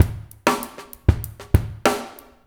PRP DR1DRY-R.wav